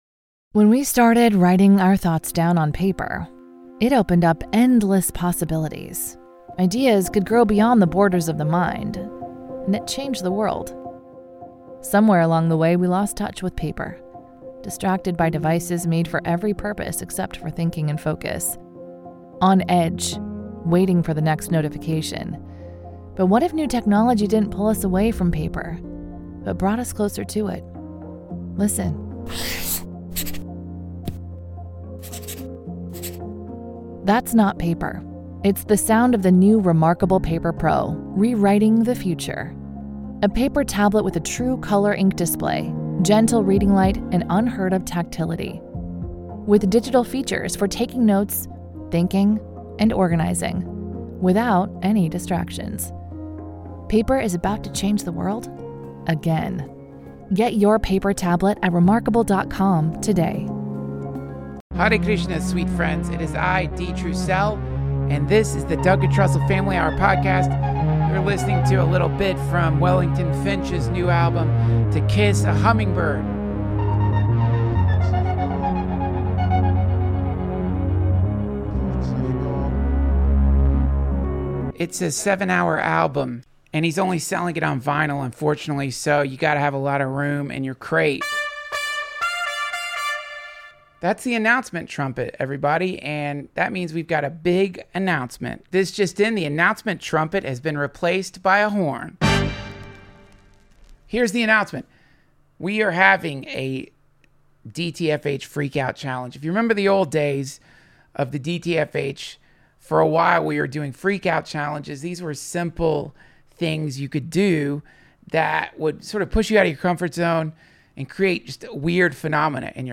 Sharon Salzberg, dharma teacher and a great spiritual leader of our time, re-joins the DTFH!